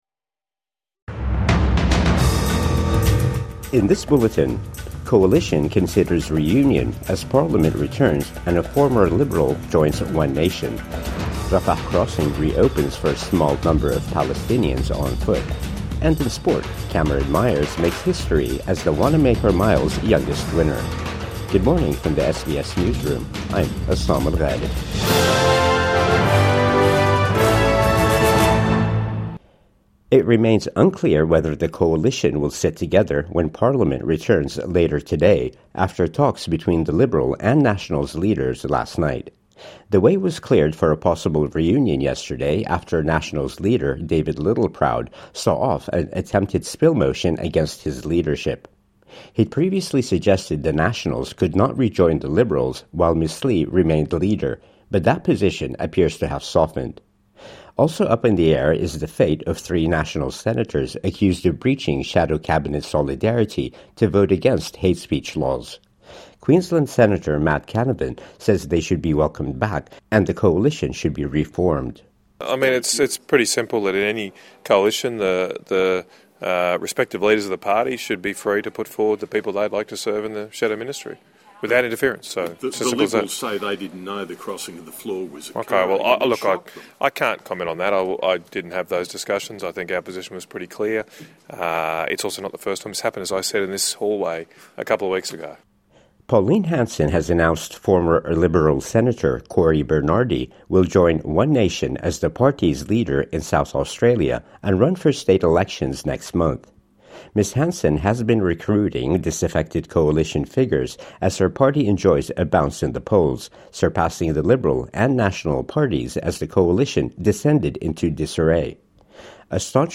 Listen to Australian and world news and follow trending topics with SBS News Podcasts.